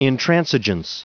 Prononciation du mot intransigence en anglais (fichier audio)
Prononciation du mot : intransigence